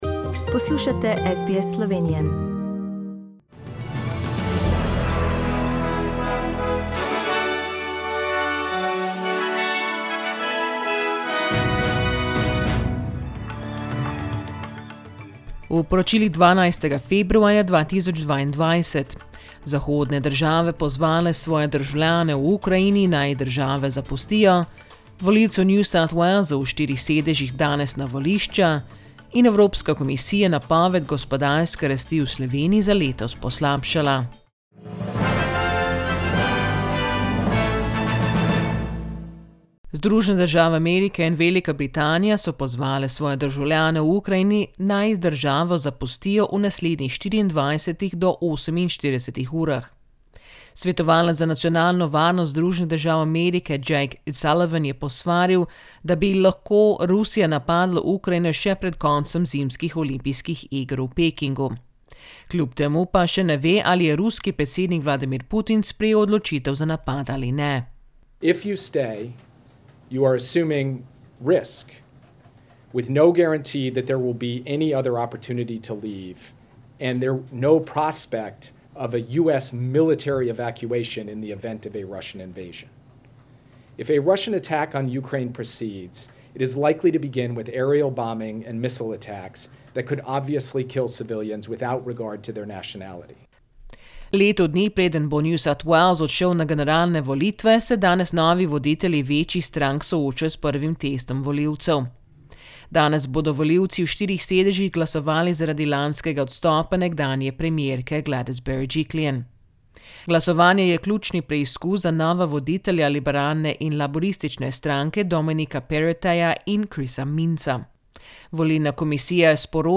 Poročila Radia SBS v slovenščini 12.februarja